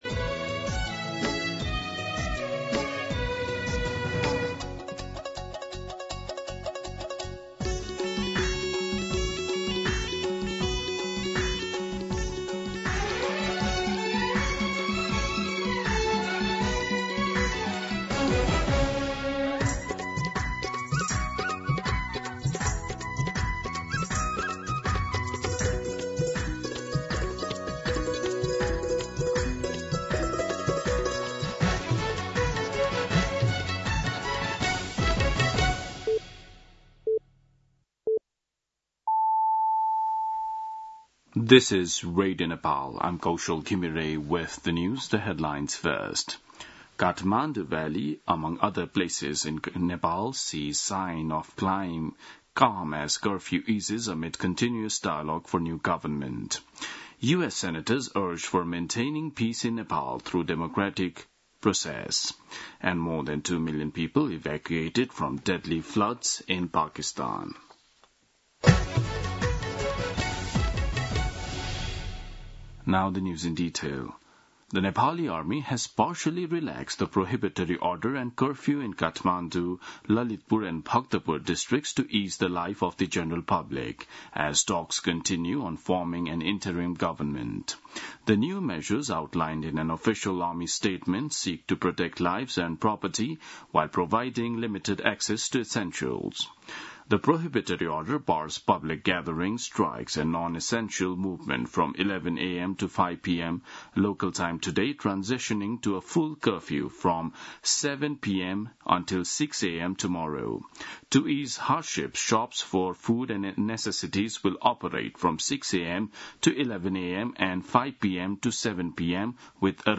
दिउँसो २ बजेको अङ्ग्रेजी समाचार : २७ भदौ , २०८२